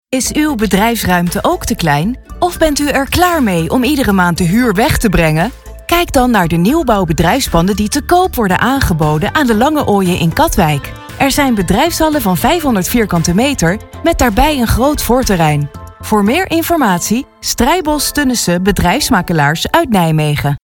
Sou uma locutora profissional holandesa com uma voz clara, calorosa e fresca.